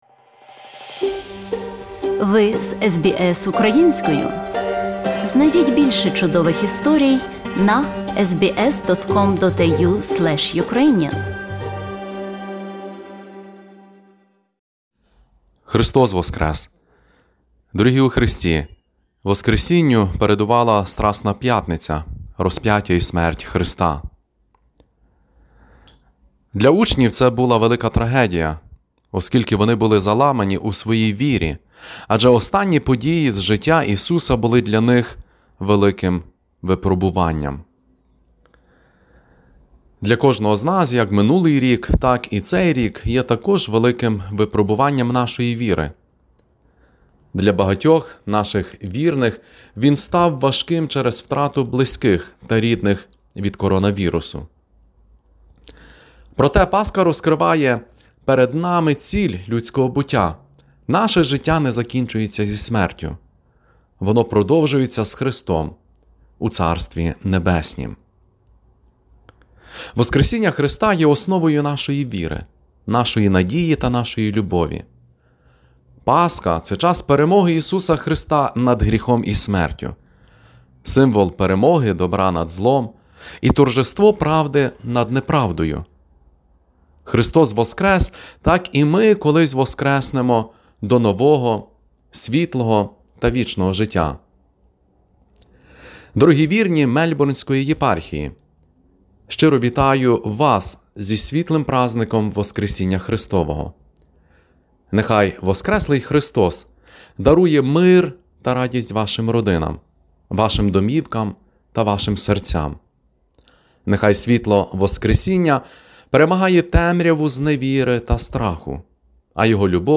Великоднє привітання Єпископа Миколи Бичка
Єпископ мельбурнський владика Микола Бичок вітає українців Австралії з Паскою.